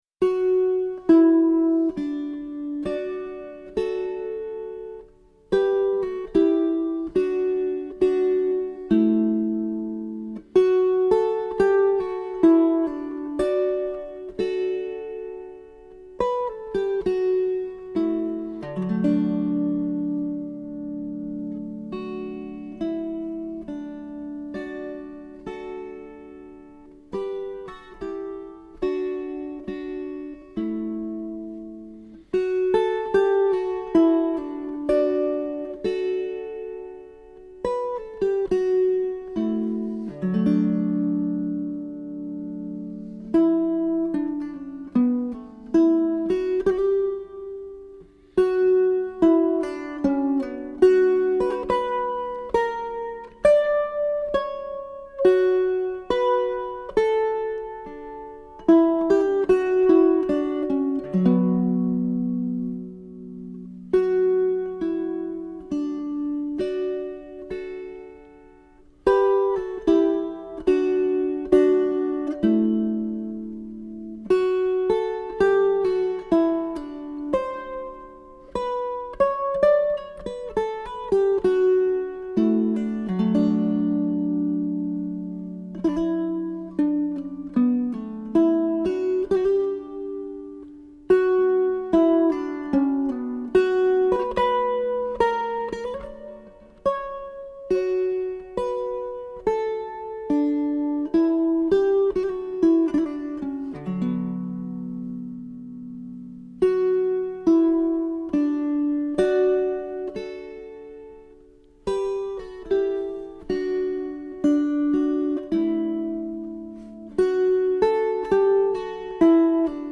18th-century wire-strung guittar